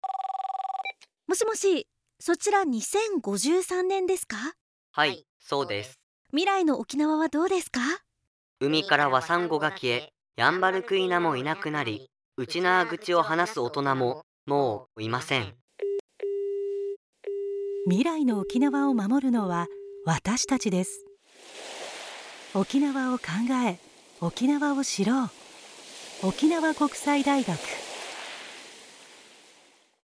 ラジオCMは